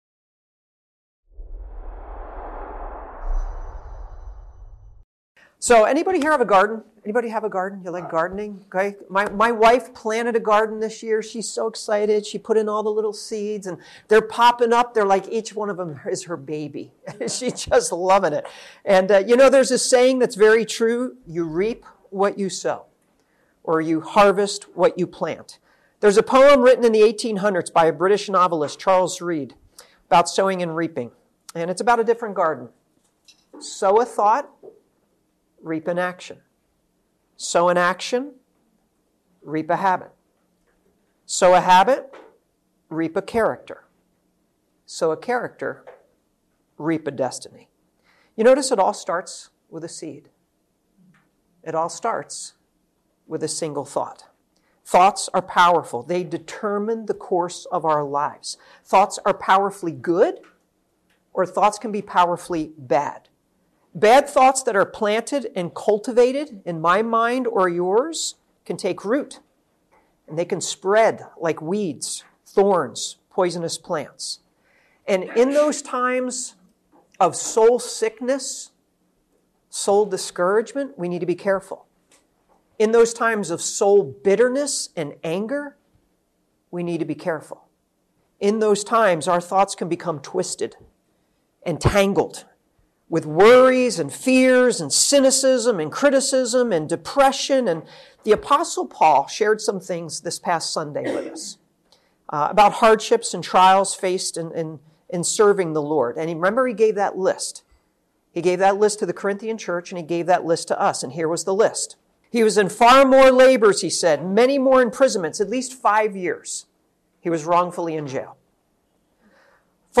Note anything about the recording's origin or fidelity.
Event: ELF Soul Care Network